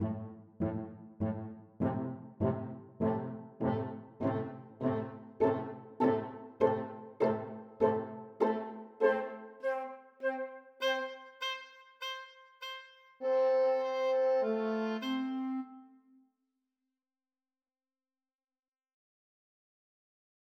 노먼 델 마는 그의 6번 교향곡의 스케르초 악장에서 반복되는 음표가 호른, 피치카토 현악기, 트럼펫, 클라리넷, 플루트, 피콜로, 오보에 등 다양한 악기를 통해 전달되는 방식을 설명한다.